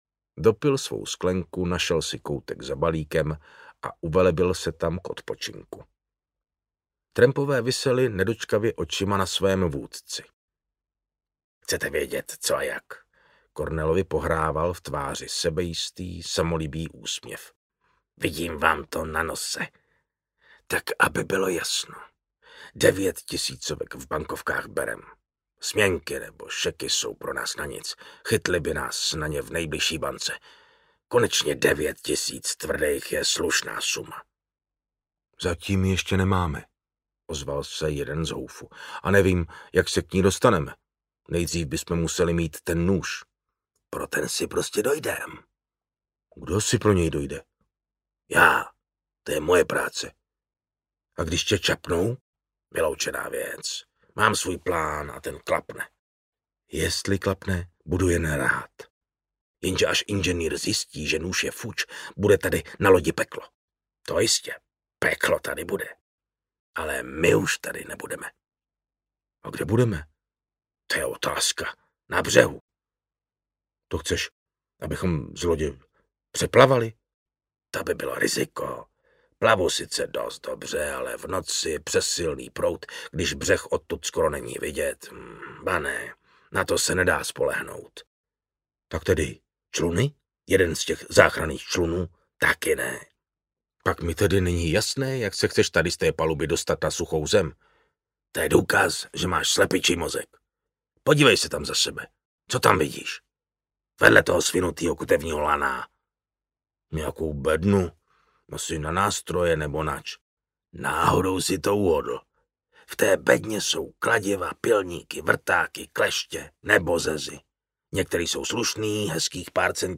Poklad ve Stříbrném jezeře audiokniha
Ukázka z knihy
• InterpretMartin Finger